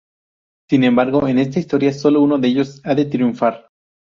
Pronúnciase como (IPA)
/tɾjunˈfaɾ/